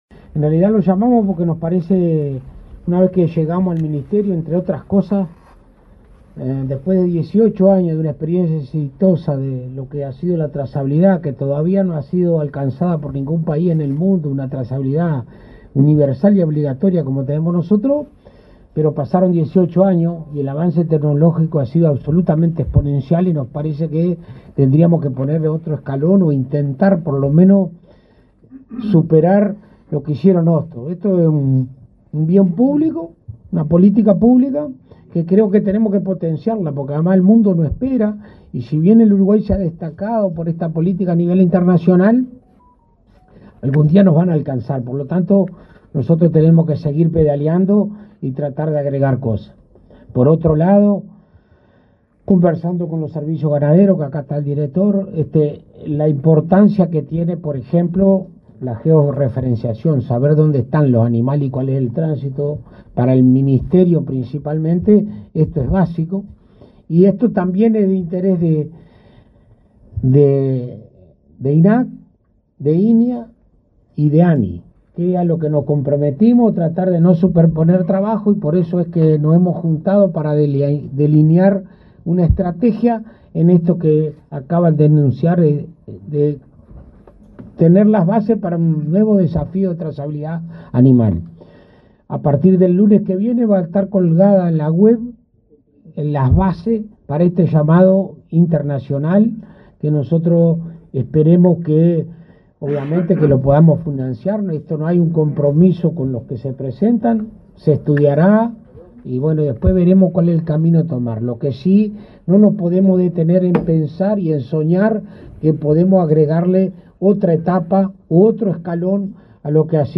Palabras de autoridades en acto en el Ministerio de Ganadería
El ministro de Ganadería, Agricultura y Pesca, Alfredo Fratti, y los presidentes del Instituto Nacional de Investigación Agropecuaria, Miguel Sierra; el Instituto Nacional de Carnes, Gastón Scayola, y la Agencia Nacional de Investigación e Innovación, Álvaro Brunini, presentaron el llamado para proyectos de soluciones tecnológicas relacionadas con la trazabilidad ganadera.